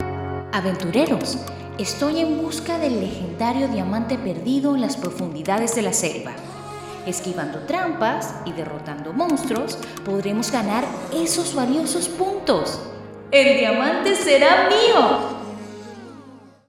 Joven, Natural, Amable, Cálida, Suave